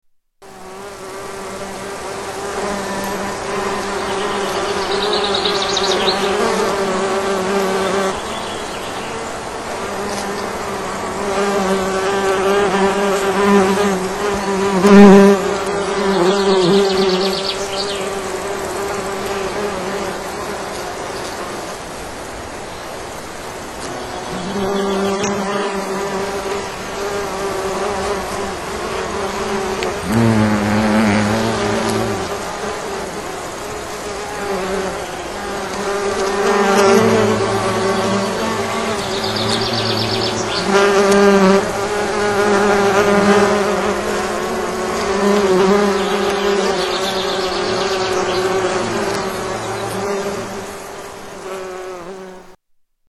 Early Nesting Bumble Bees
Category: Animals/Nature   Right: Personal
Tags: Science and Nature Wildlife sounds Bristish Animals British Wildlife sounds United Kingdom